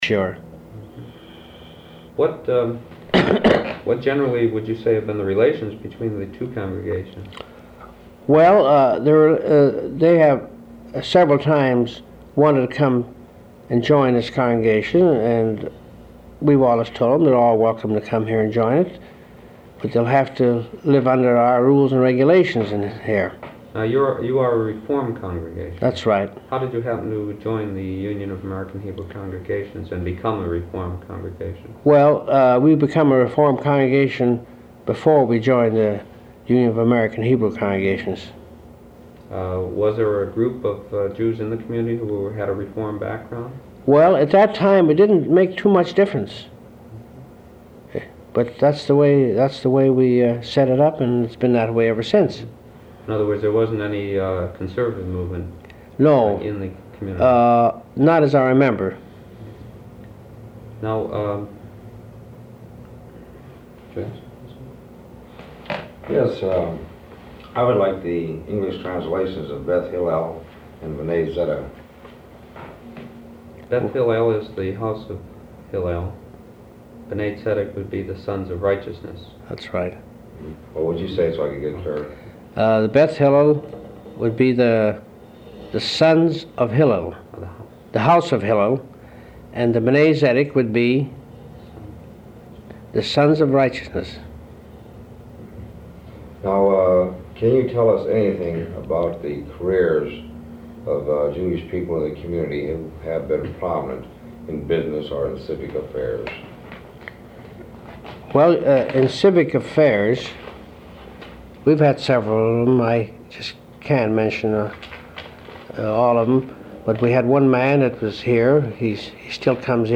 Wisconsin Historical Society Oral History Collections